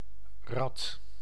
Ääntäminen
Synonyymit wiel Ääntäminen : IPA: /rɑt/ Haettu sana löytyi näillä lähdekielillä: hollanti Käännöksiä ei löytynyt valitulle kohdekielelle.